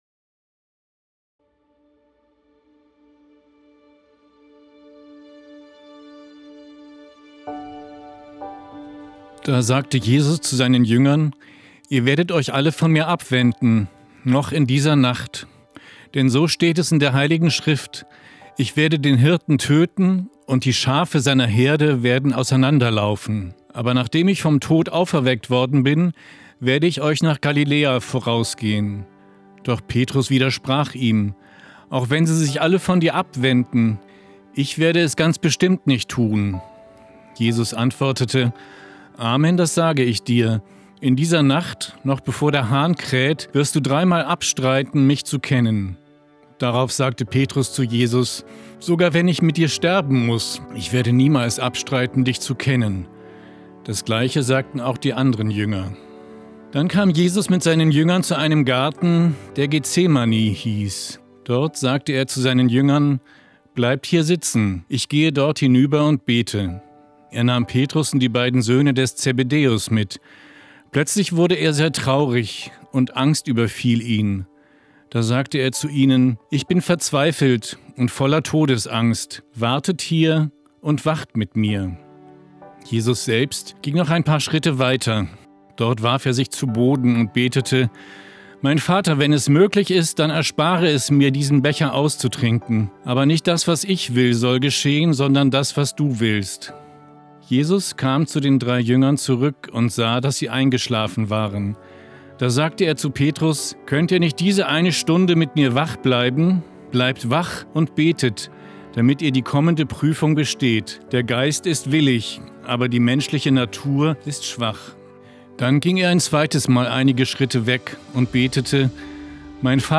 Der Evangelische Kirchenfunk Niedersachsen (ekn) hat die Aufnahmen mit ihm produziert und musikalisch unterlegt.